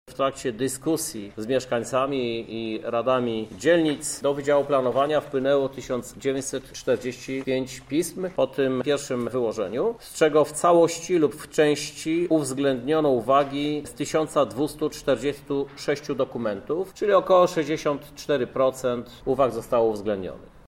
– mówi Prezydent Miasta Lublin Krzysztof Żuk.